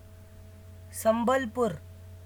English: This is the pronunciation for Sambalpur